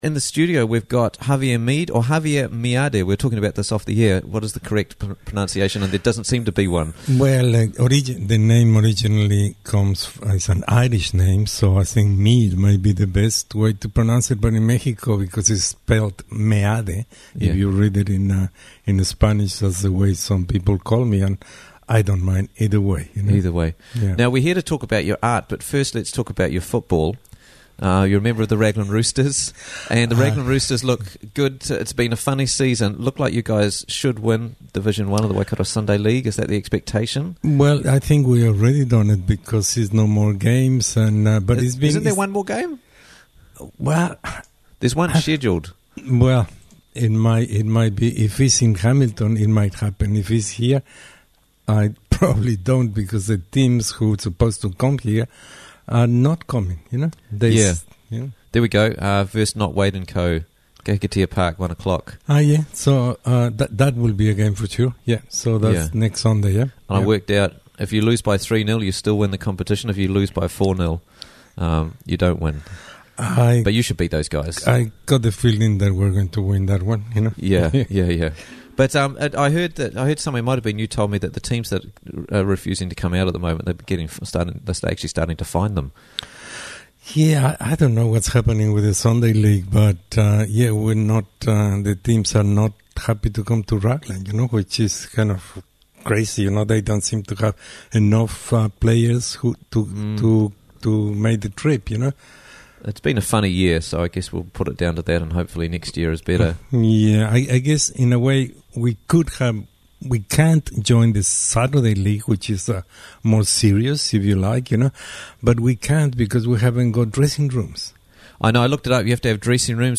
Artist Talk at the Old School Tonight